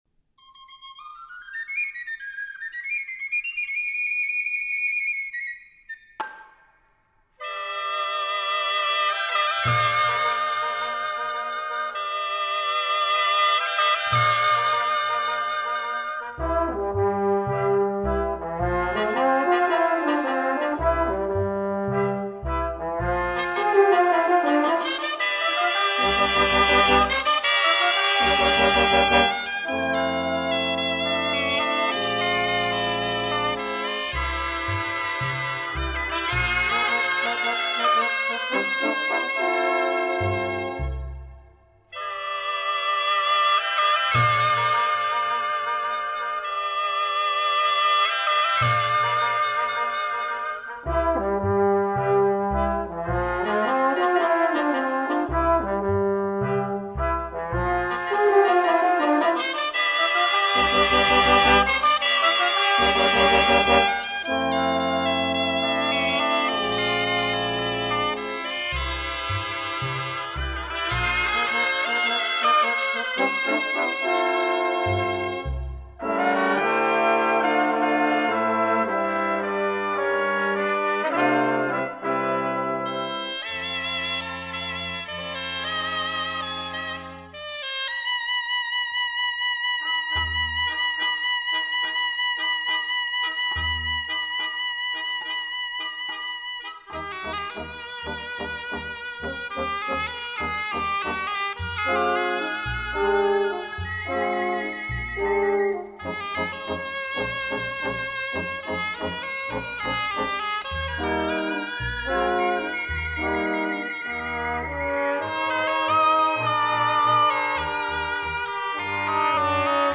Exemple de L’influencia del Jazz